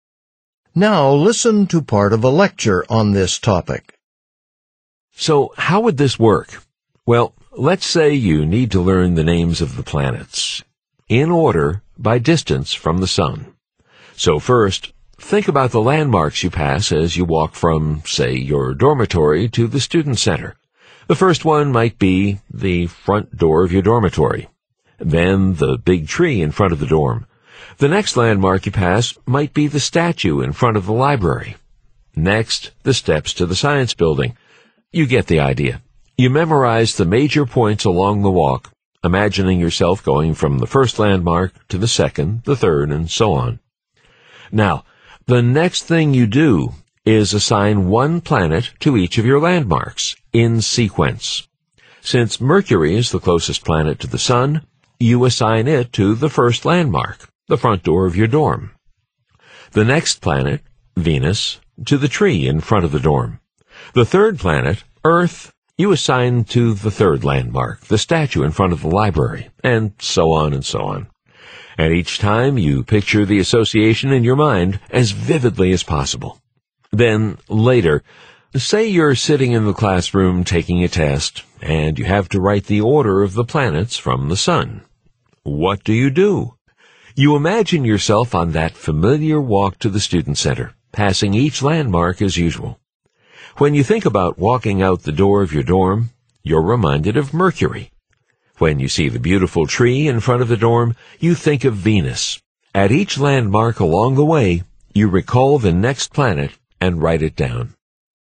Using the professor’s example, explain how the method of loci is used to recall information in sequence.
tpo45_speaking4_question4_dialog.mp3